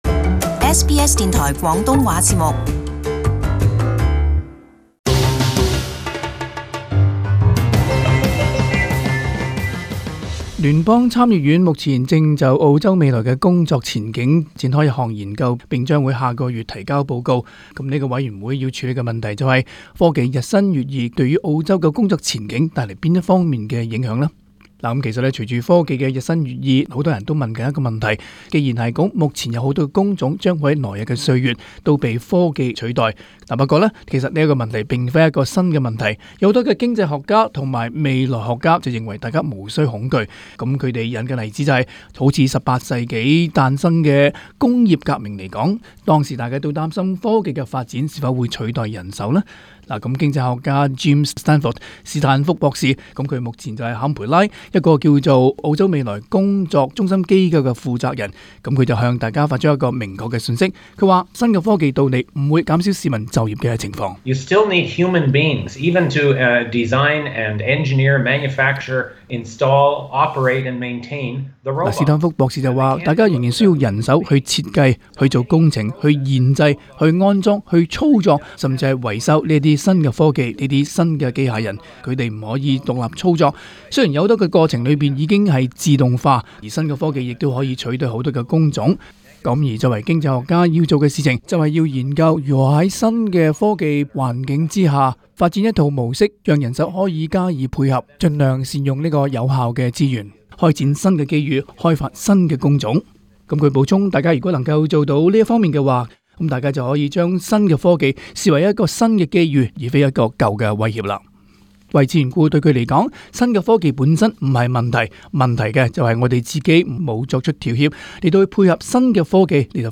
【時事報導】科技進步會否取代人手？